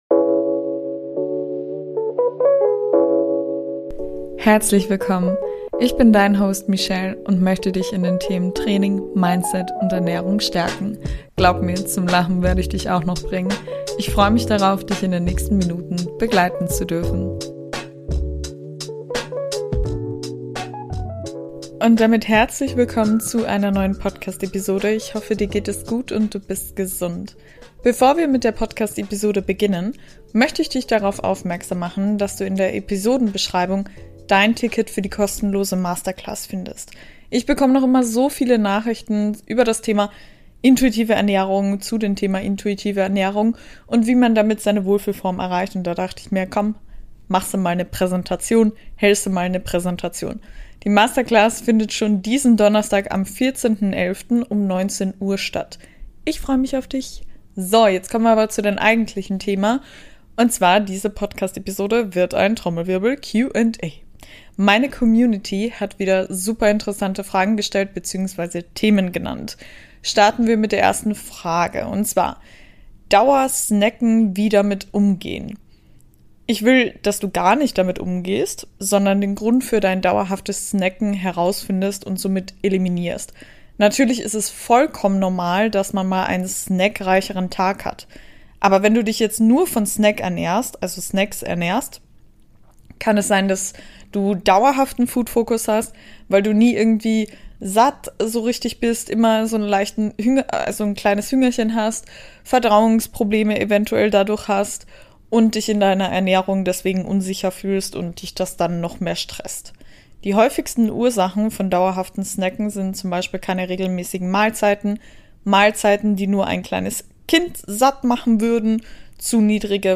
TW: DROGENSUCHT In der heutigen Podcastepisode erwartet dich ein Q&A. Lass mir unbedingt eine Bewertung bei Apple Podcast & bei Spotify da!